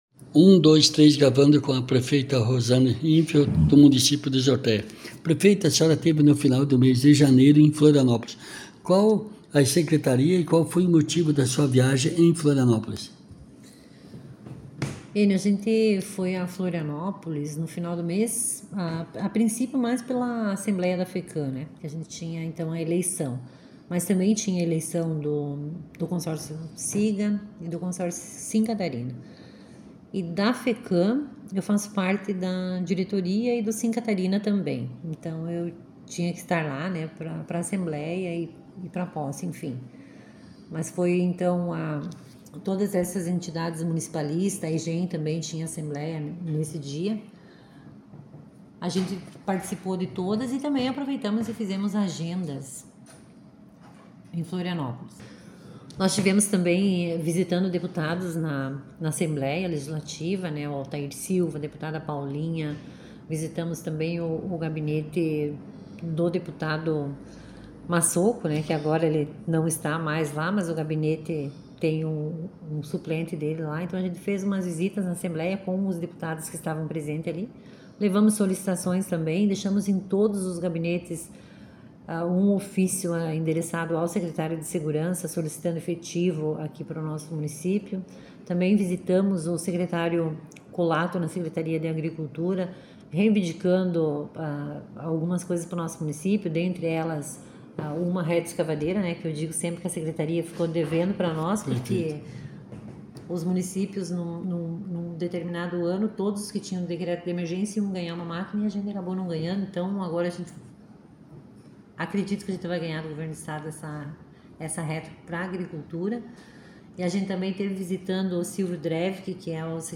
A Reportagem de O TEMPO jornal de fato, terça-feira, 11 de fevereiro, entrevistou a prefeita do município de Zortéa, Rosane Infeld, a qual no final do mês de janeiro esteve na capital do estado de Santa Catarina: Florianópolis.